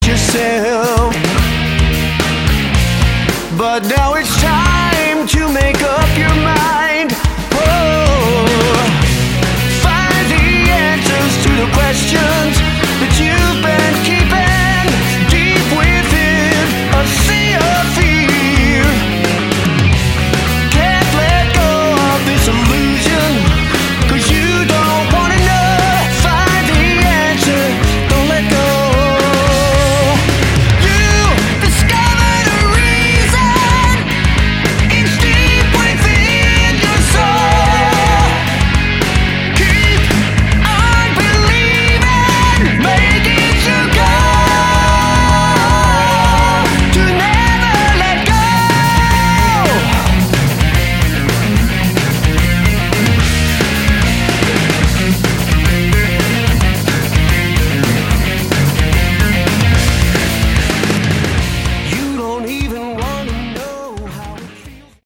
Category: AOR / Prog
guitars, bass, keyboards
vocals
drums